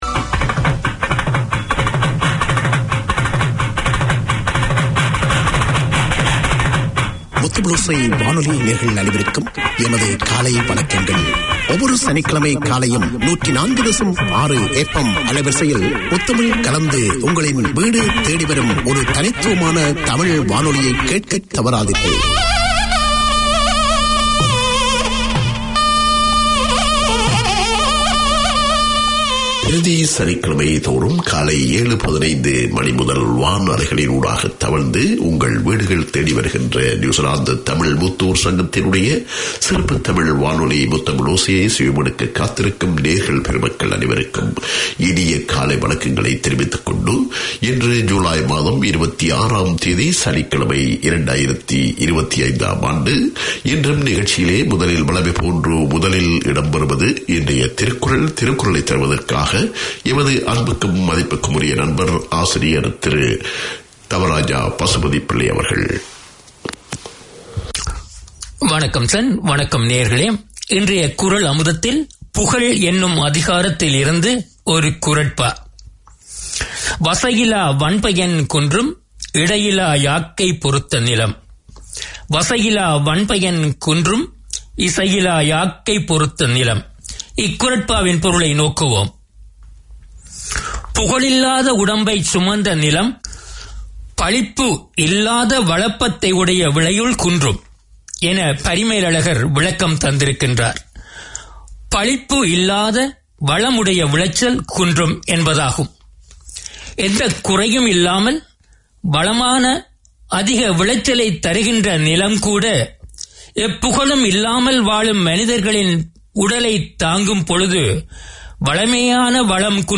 Tamil seniors are up early every Saturday to hear their half hour of radio. On air is a wealth of interviews, music, news, community news, health information, drama and literature.